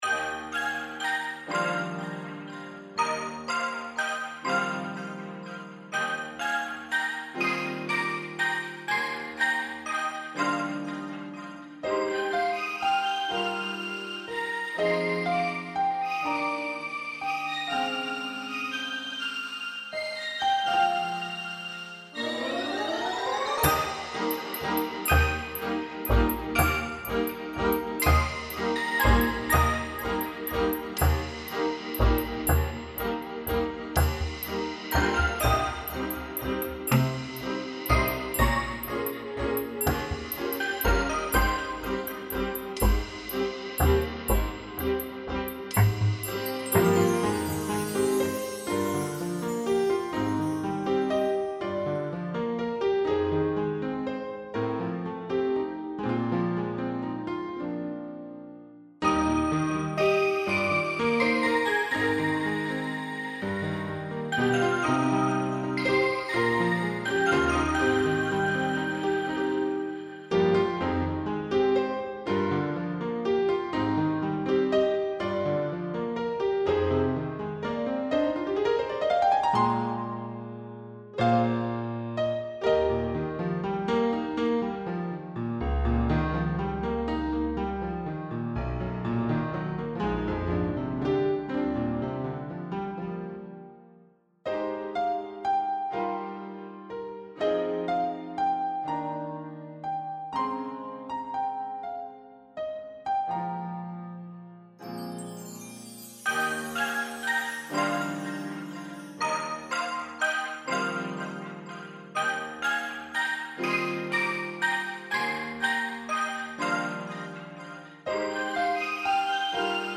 BGM
ロング明るい穏やか